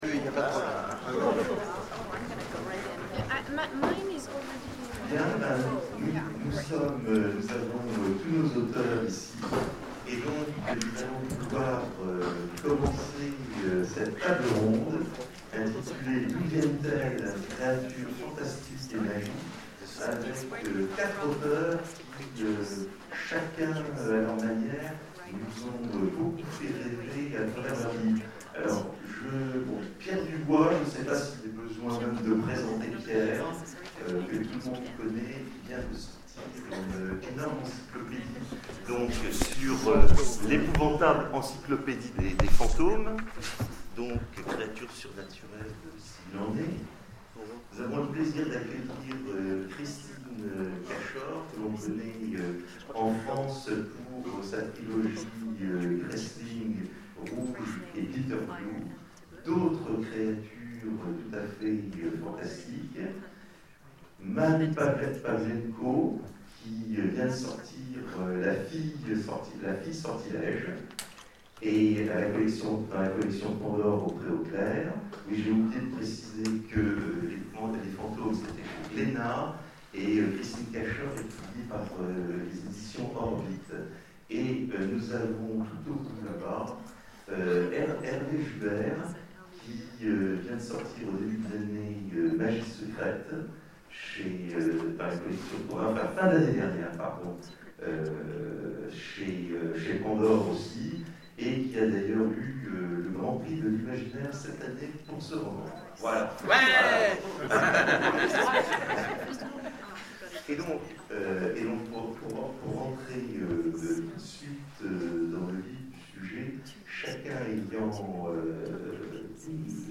Etonnants Voyageurs 2013 : Conférence D'où viennent-elles ? Créatures fantastiques et magiques